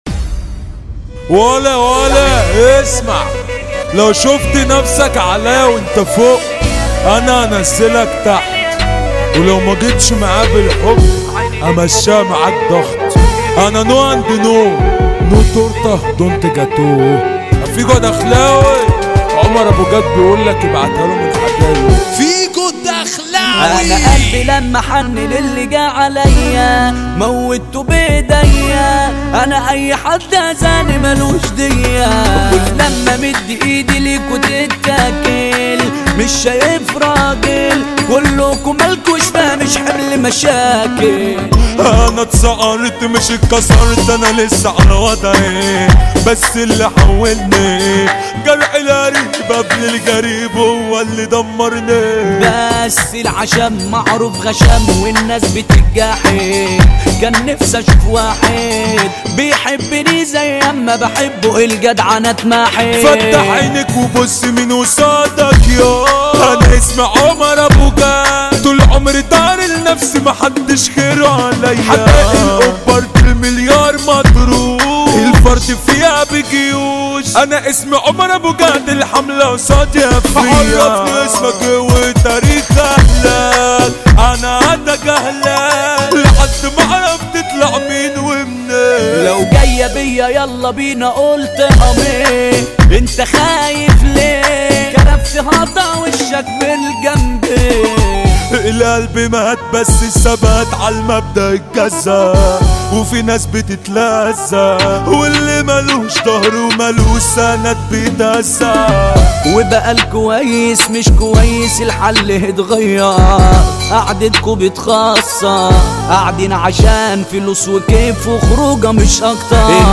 مهرجان
بصوت الفنان